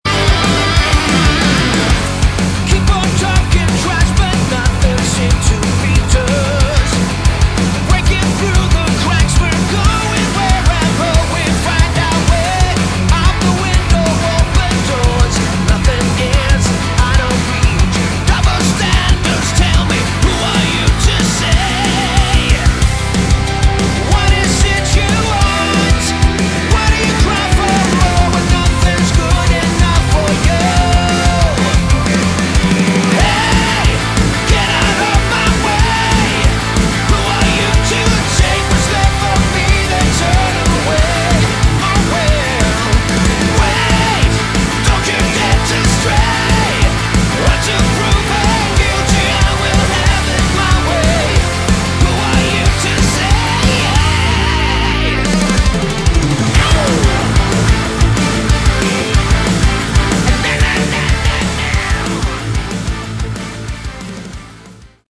Category: Melodic Rock
bass, vocals
drums
keyboards
guitars